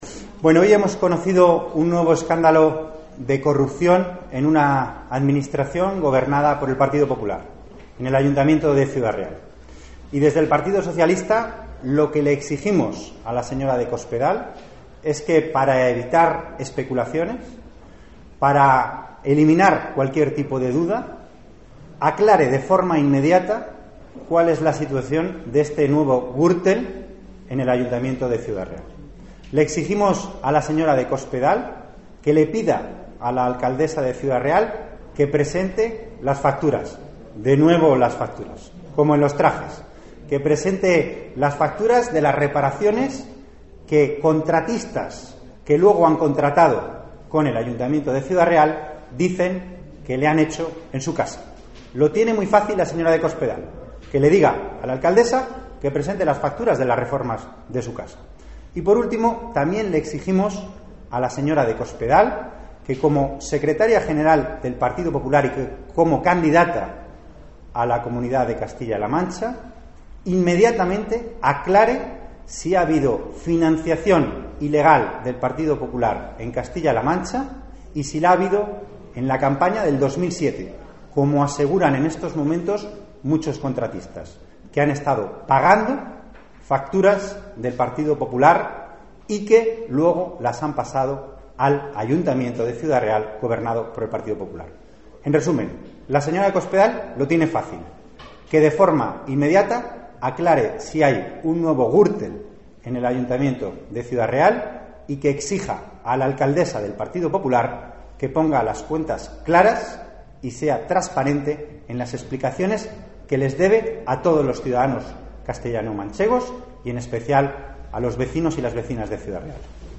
Antonio Hernando, que ha ofrecido una rueda de prensa en la sede del PSOE en Murcia, ha insistido en pedir a Cospedal que, «para evitar especulaciones y eliminar cualquier tipo de duda, aclare de forma inmediata cual es la situación de este nuevo Gürtel en el ayuntamiento de Ciudad Real».
Cortes de audio de la rueda de prensa
audio_antonio_hernando.mp3